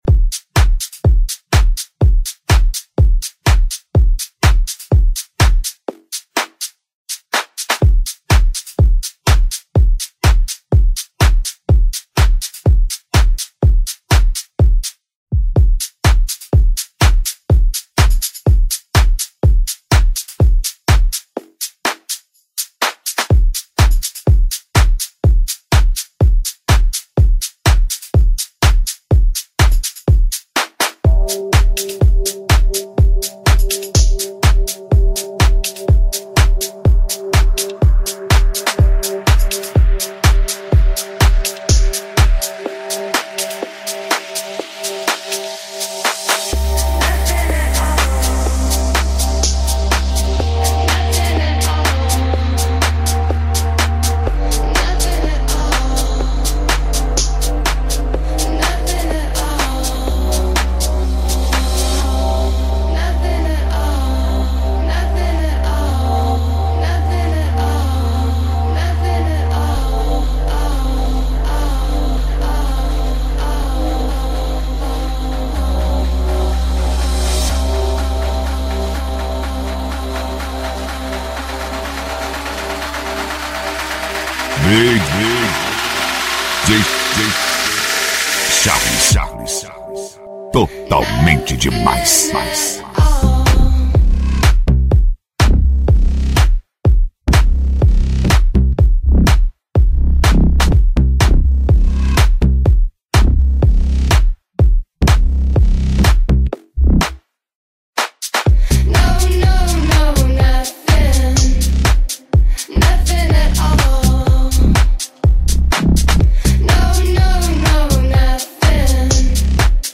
DEEP HOUSE.